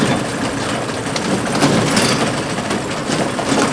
ELEVATOR.WAV